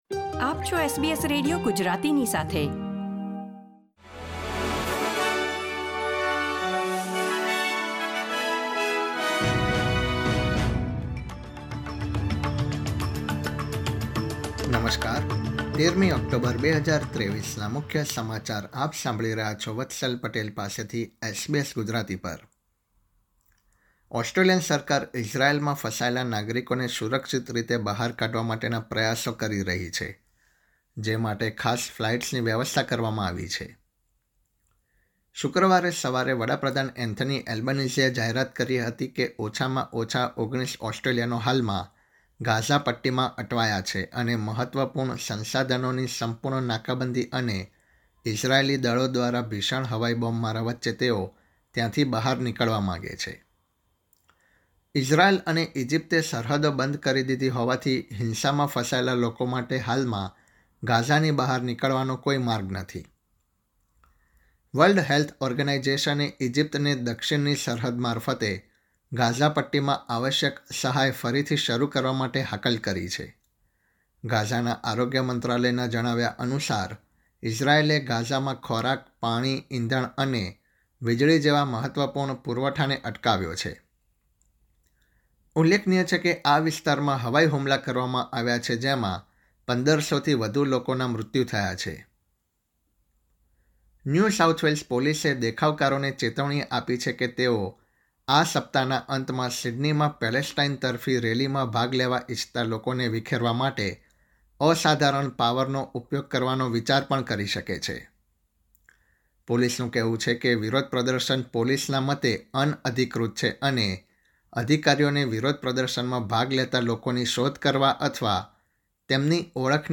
SBS Gujarati News Bulletin 13 October 2023